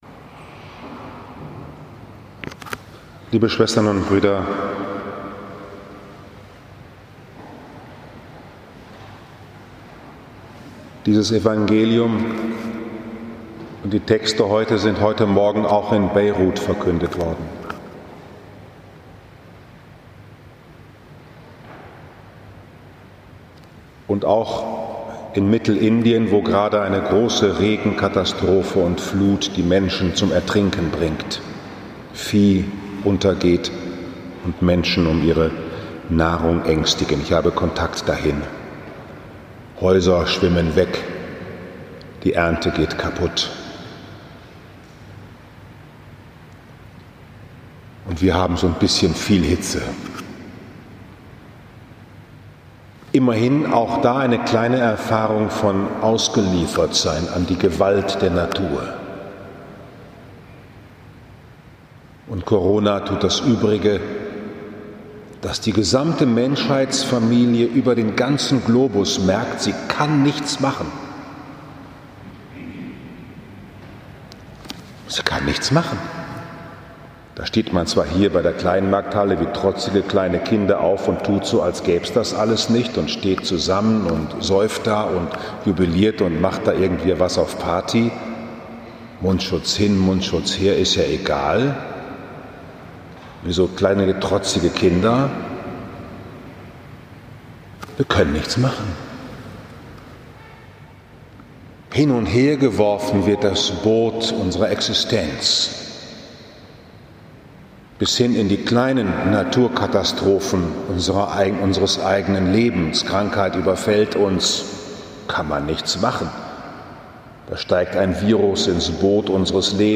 Kapuzinerpredigt Podcast
In den Erschütterungen der Naturgewalten die Auferstehungskraft glauben Wie wir uns bergen können im Mitsein des Erlösers in all unseren Untergängen 9. August 2020, 11 Uhr Liebfrauenkirche Frankfurt am Main, 18. Sonntag im Jahreskreis A